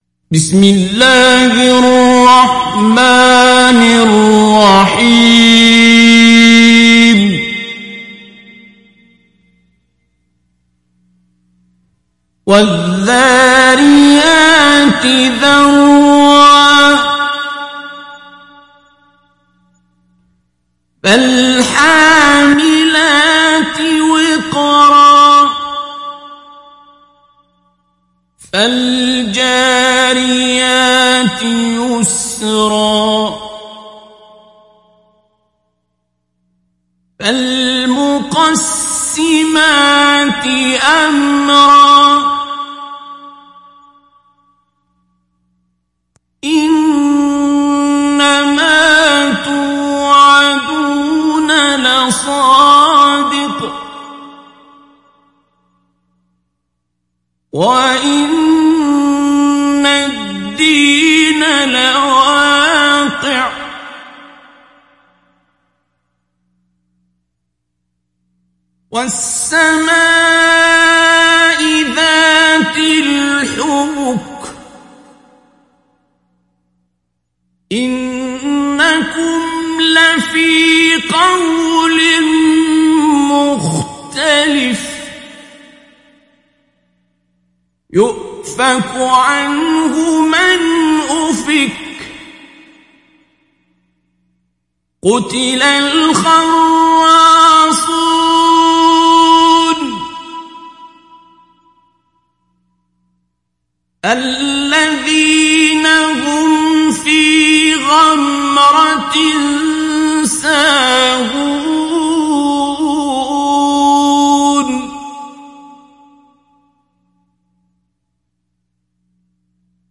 Download Surat Ad Dariyat Abdul Basit Abd Alsamad Mujawwad